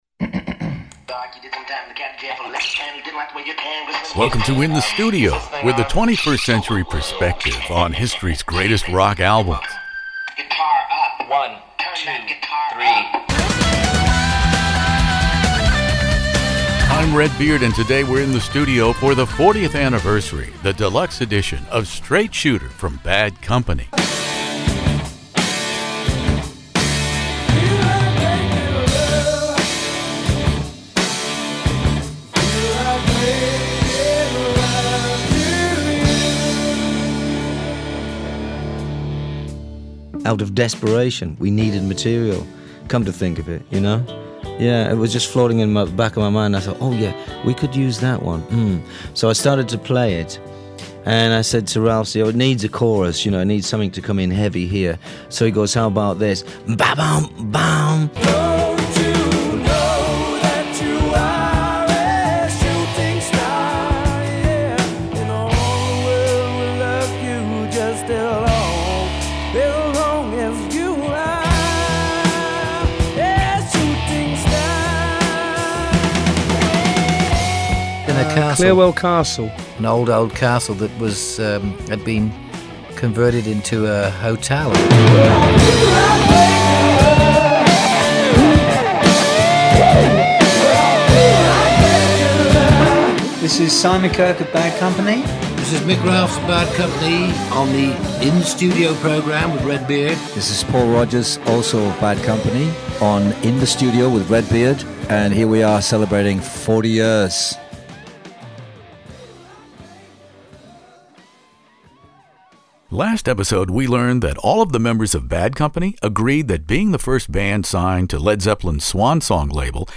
Bad Company "Straight Shooter" interview with Paul Rodgers